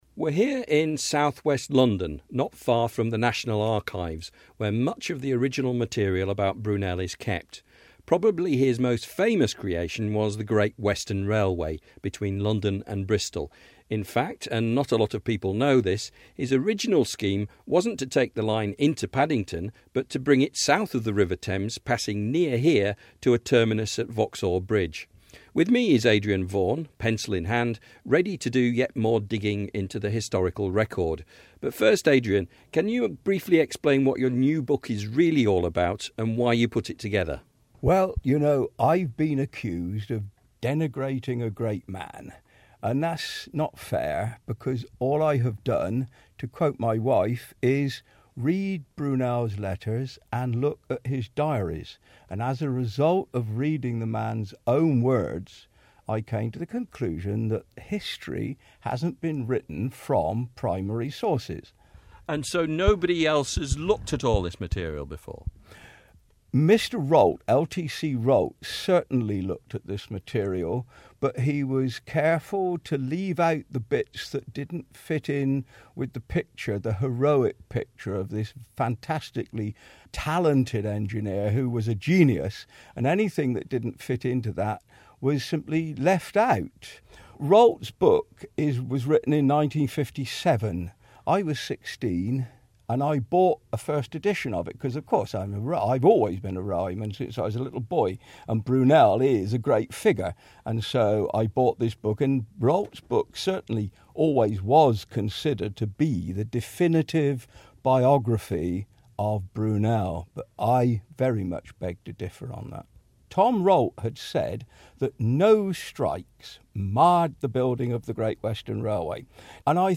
Railway historian